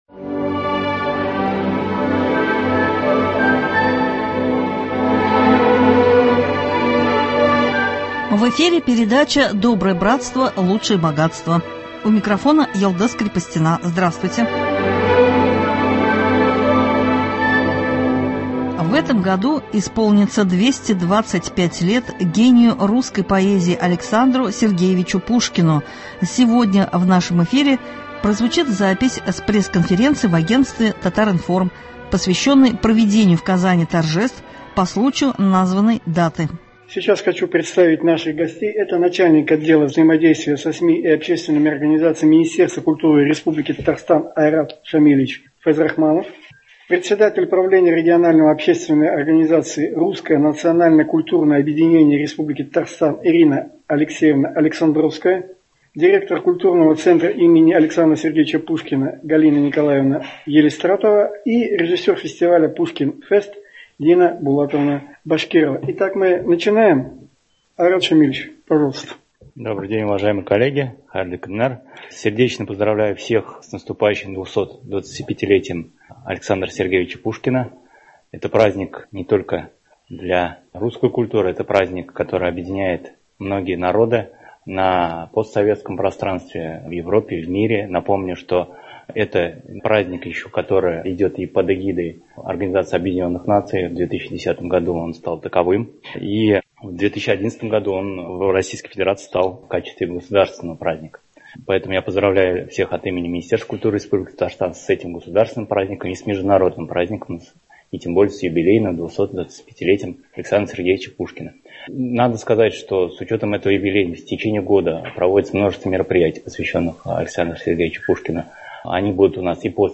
В этом году исполнится 225 лет гению русской поэзии Александру Сергеевичу Пушкину. Сегодня в нашем эфире прозвучит запись с пресс — конференции в агентствке «Татар-информ» , посвященной проведению в Казани мероприятий к этой дате.